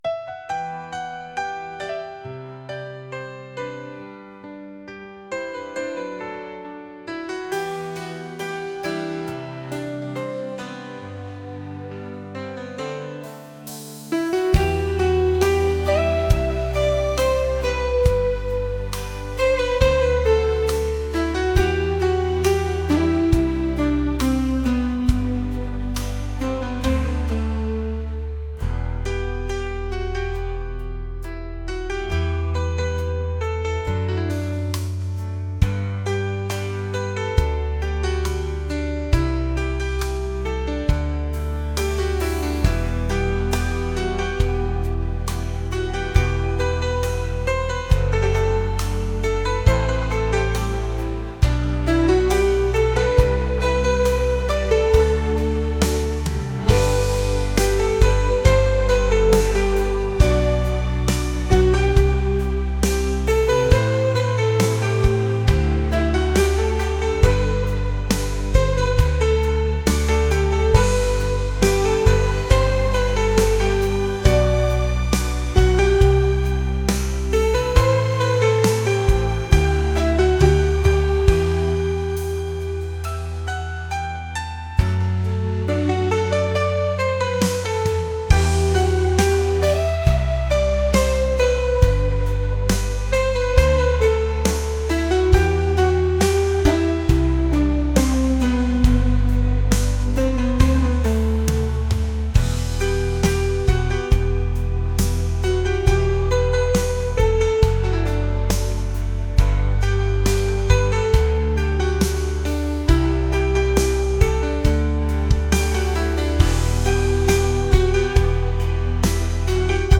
acoustic | pop | soul & rnb